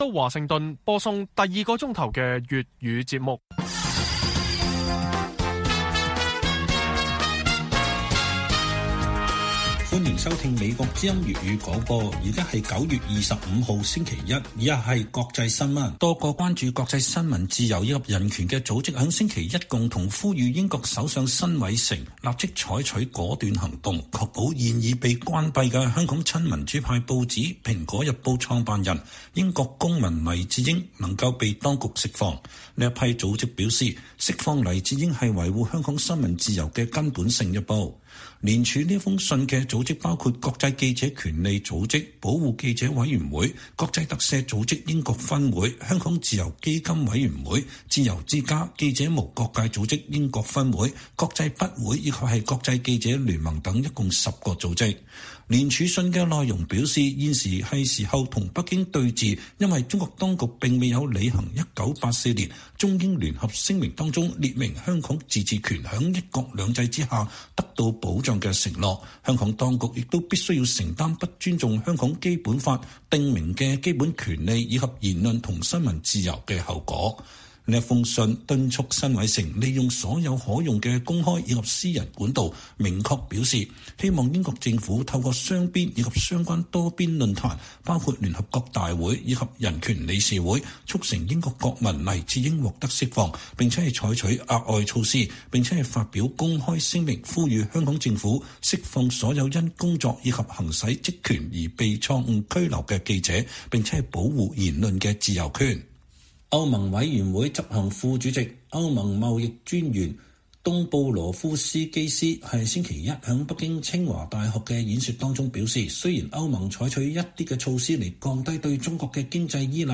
粵語新聞 晚上10-11點: 香港前民主派區議員組在海外繼續爭取港人權利